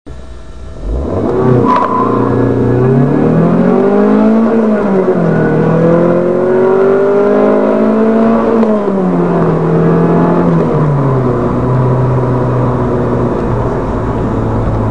Opel Astra Gsi (MP3 102Ko)